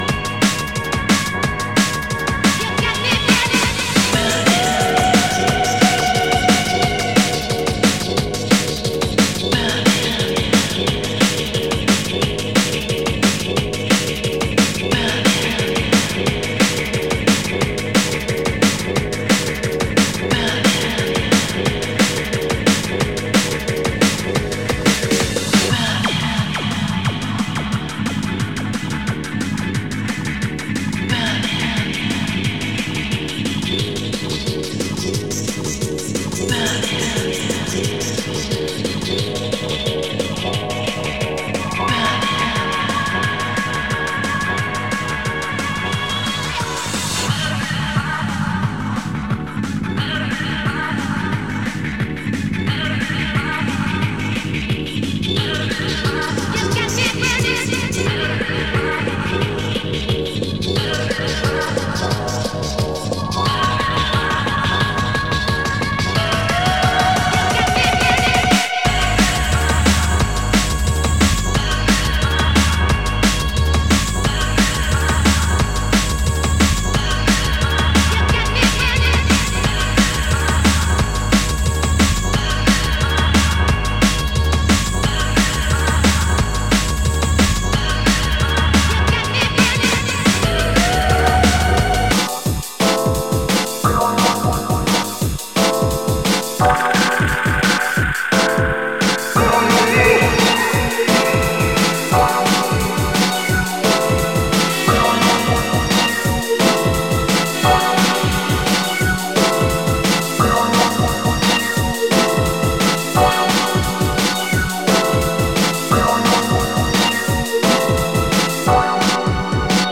Category: Drum N Bass